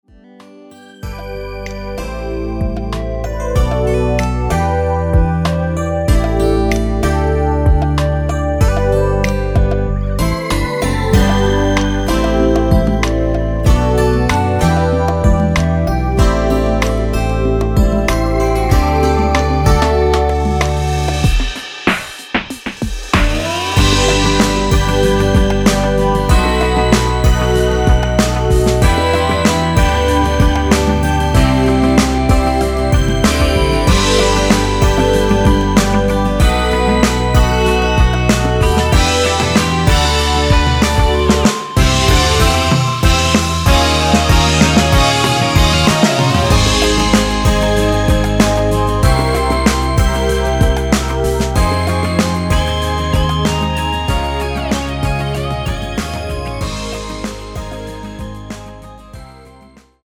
원키에서(+3)올린 (짧은편곡) 맬로디 포함된 MR입니다.(미리듣기 참고)
앞부분30초, 뒷부분30초씩 편집해서 올려 드리고 있습니다.
(멜로디 MR)은 가이드 멜로디가 포함된 MR 입니다.